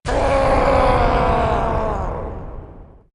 howlofterror.mp3